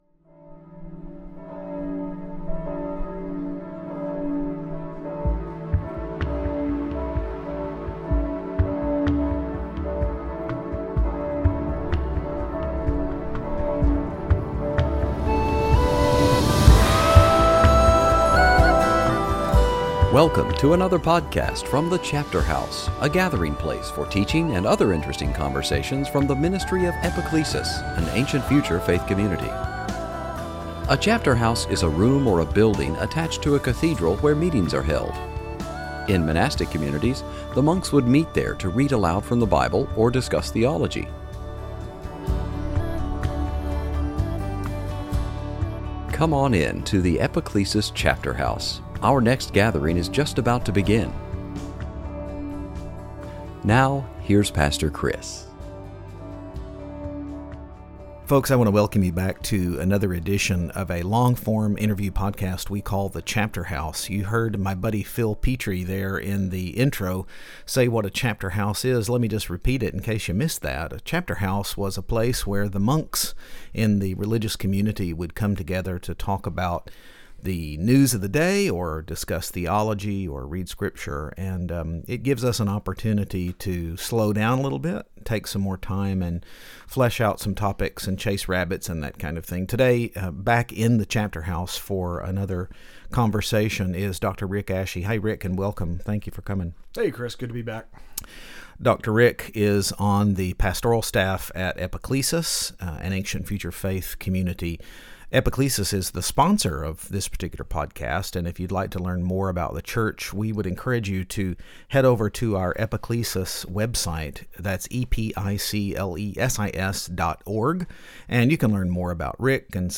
Service Type: Lent